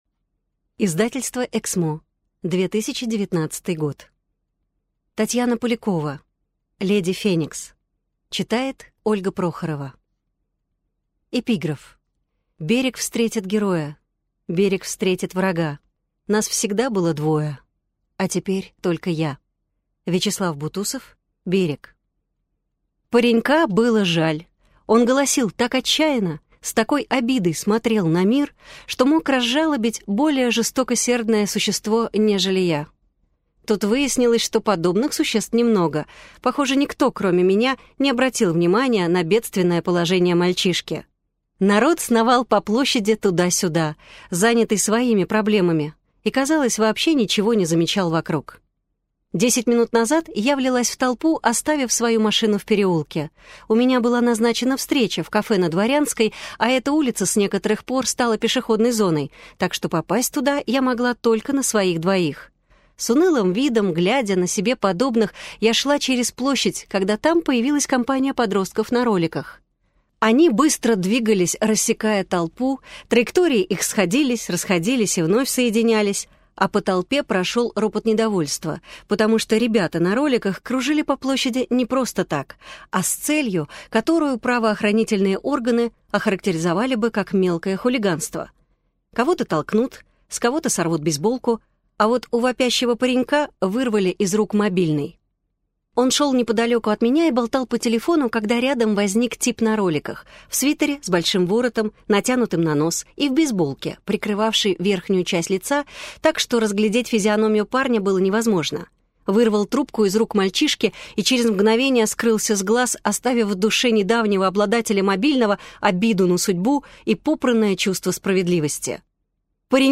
Аудиокнига Леди Феникс | Библиотека аудиокниг